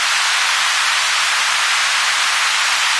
Звук радио , телевизор. Sound of radio, television set.
Звук шипение эфира.